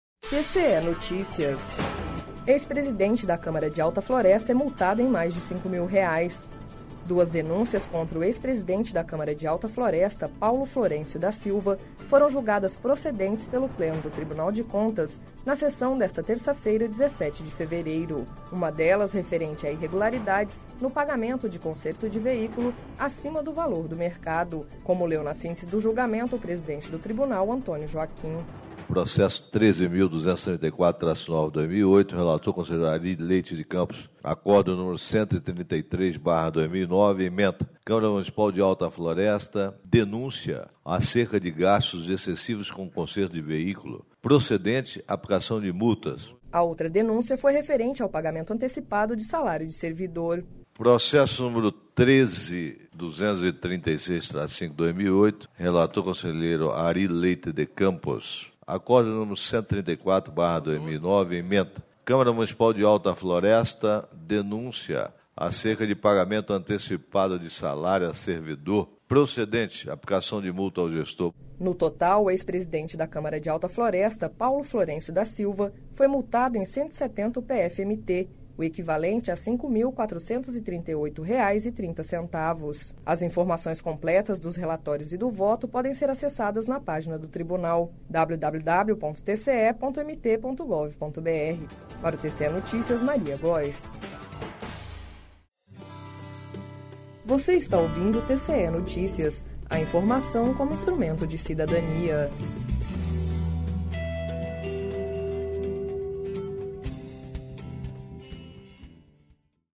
Sonora: Antonio Joaquim – conselheiro presidente do TCE-MT